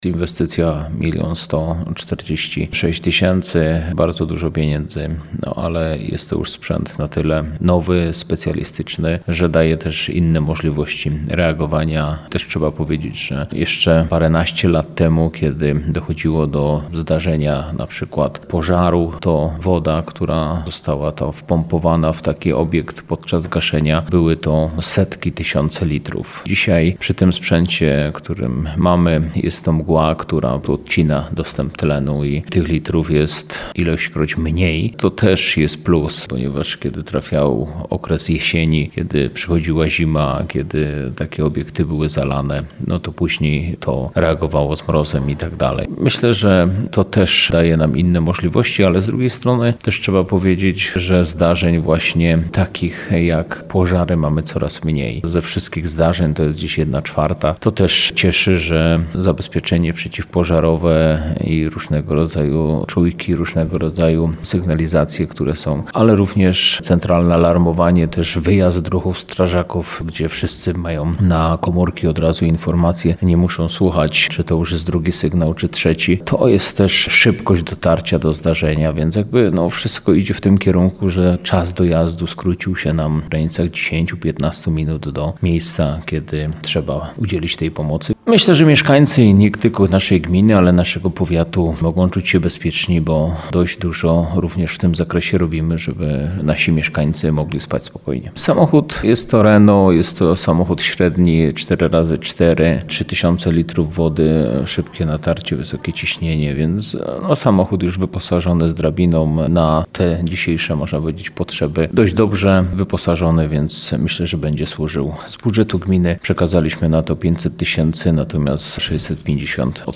O nowym samochodzie mówi Janusz Pierzyna, wójt gminy Jasienica.